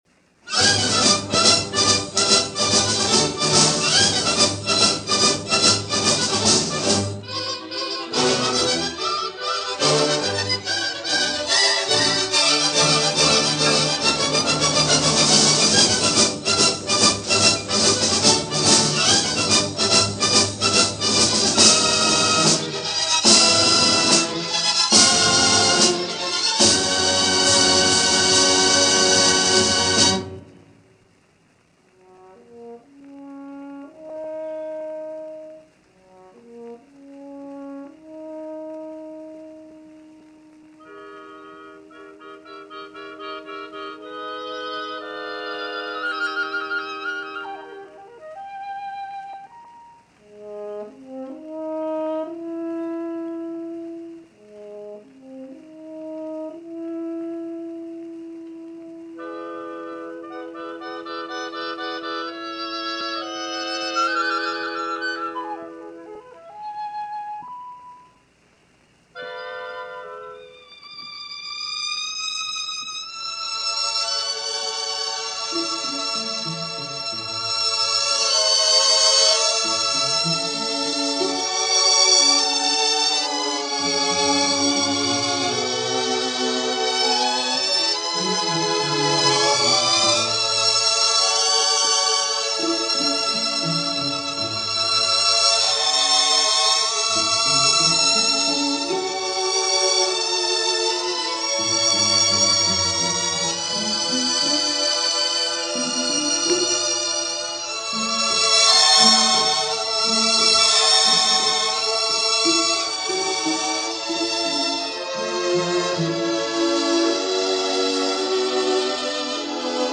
Leopold Stokowski and The L.A. Philharmonic
A familiar face around the Hollywood Bowl in the 1940s, the legendary Leopold Stokowski leads the Hollywood Bowl Symphony (L.A. Philharmonic) in a concert from August 11, 1946.
Sadly, the other parts of this concert are missing. But rather than not play it at all, I thought I would play the opening work, since the recording is quite good, considering a lot of the concerts from the Bowl around this period of time are a mixed bag, and often don’t sound all that good.